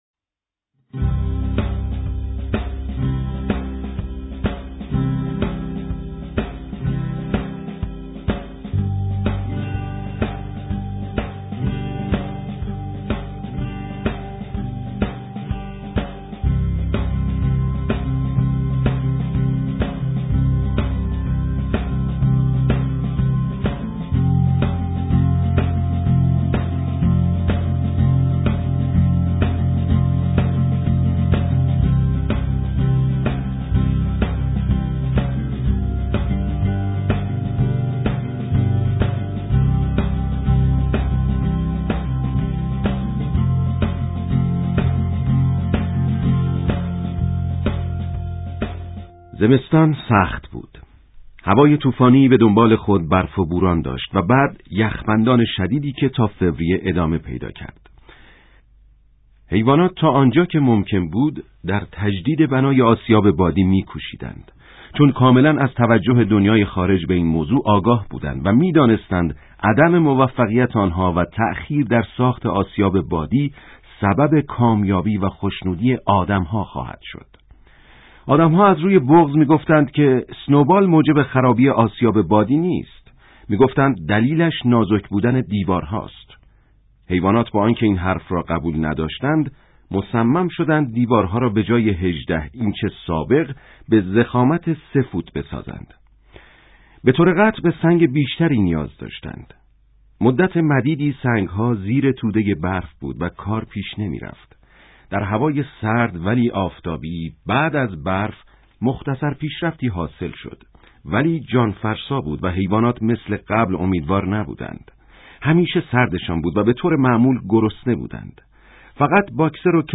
کتاب صوتی قلعه حیوانات اثر جورج اورول قسمت 7